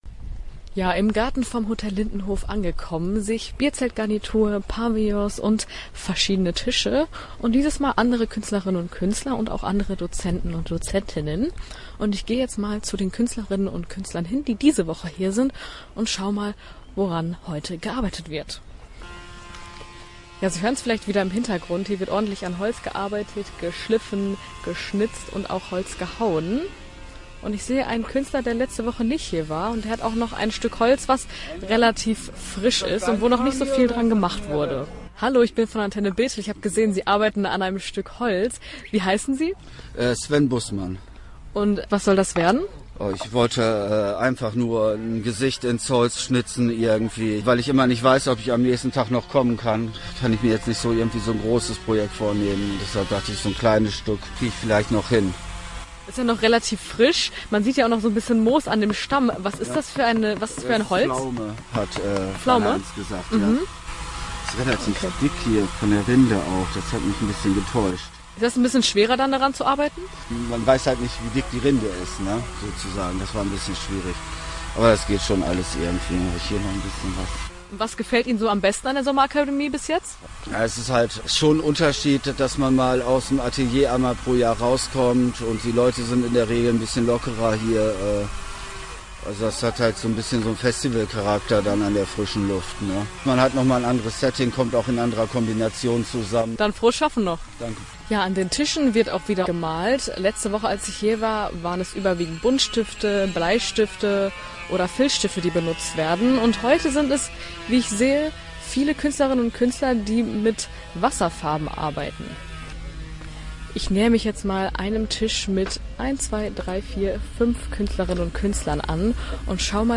Podcast-Sommerakademie-Reportage-2.-Woche-neu.mp3